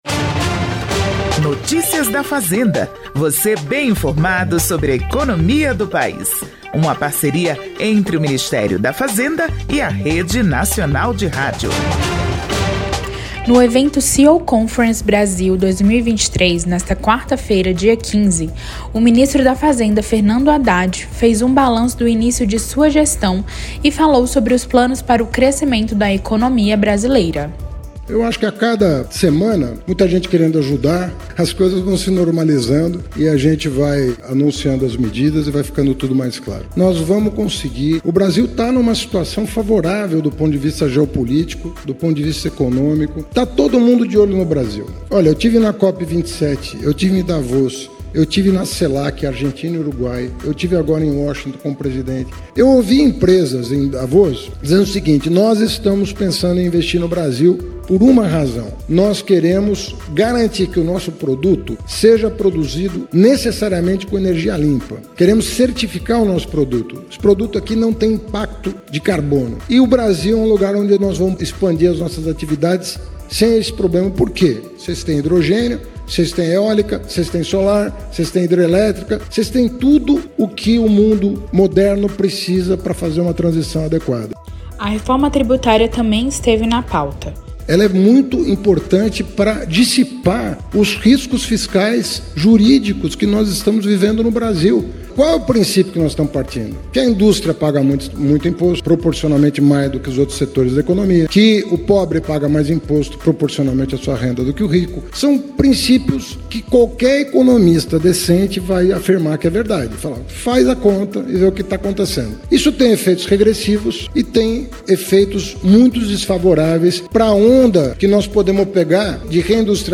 O ministro da Fazenda, Fernando Haddad, participou do evento “CEO Conference Brasil 2023", onde fez um balanço do início de sua gestão.